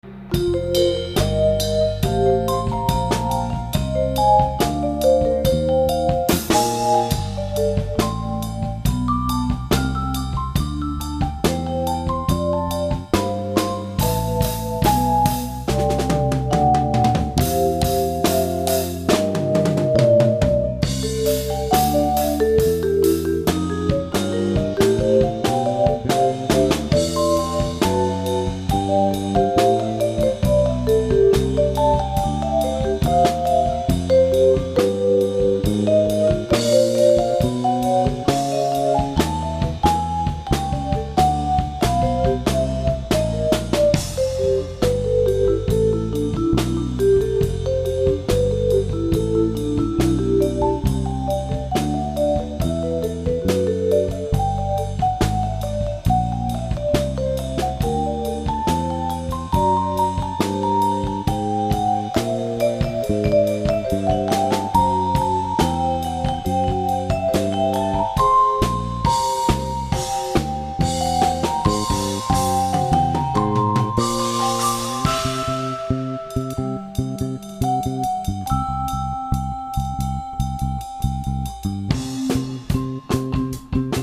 vibes
bass guitar
drumkit, triangle, gongs
All tracks recorded at Ozone Studio, Eureka, California